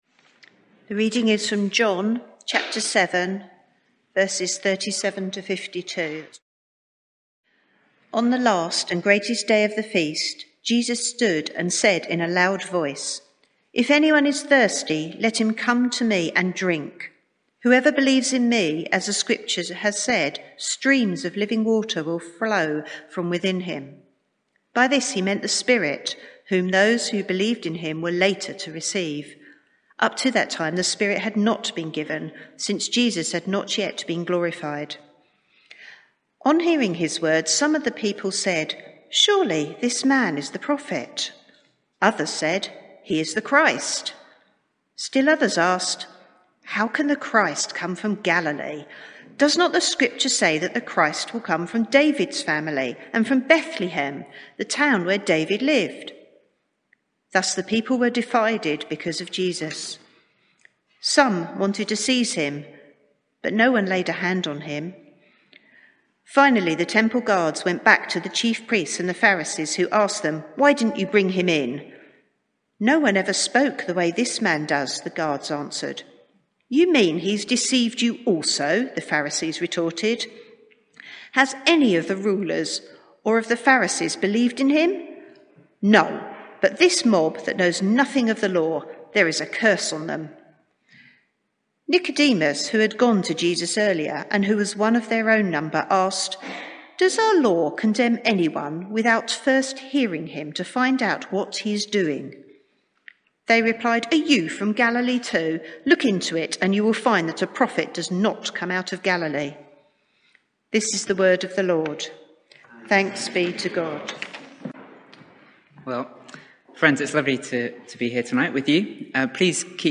Media for 6:30pm Service on Sun 02nd May 2021 18:30 Speaker
Sermon (audio)